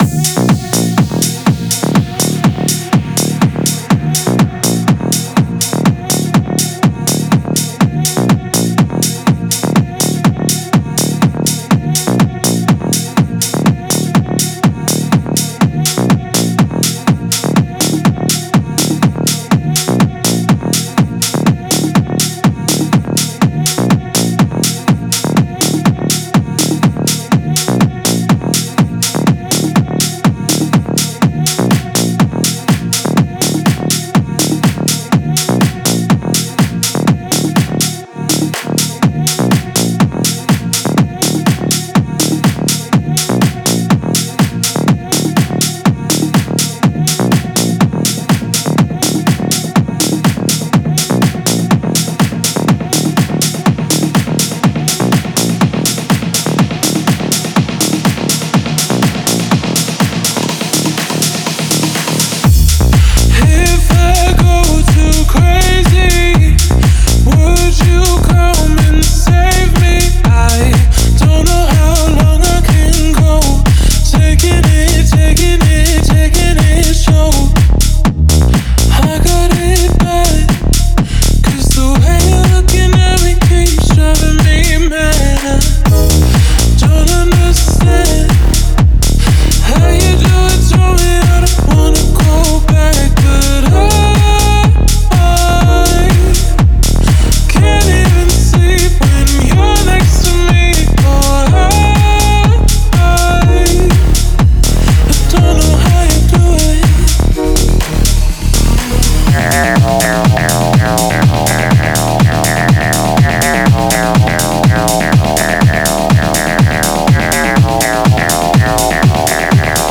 Key: G# Minor🥁 BPM: 123
Genre: Tech House